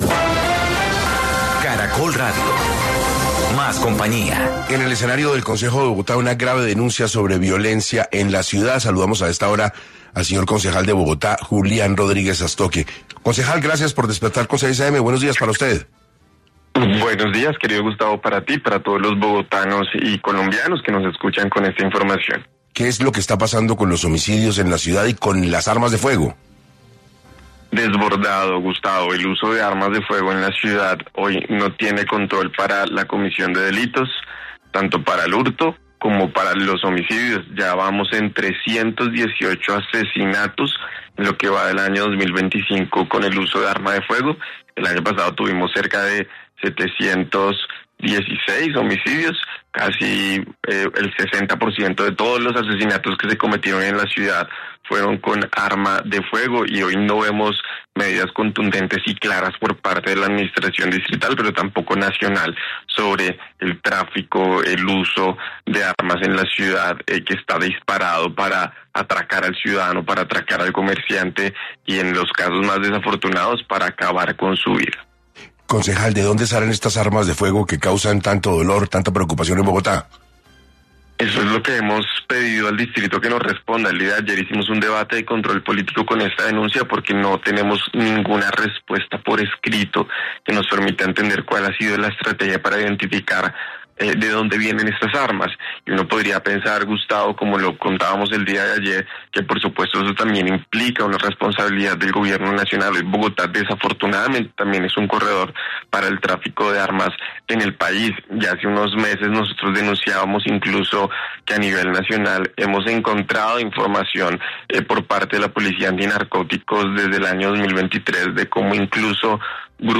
En entrevista con 6AM de Caracol Radio, el concejal de Bogotá Julián Rodríguez Sastoque lanzó una fuerte advertencia sobre la creciente ola de violencia armada en la capital del país.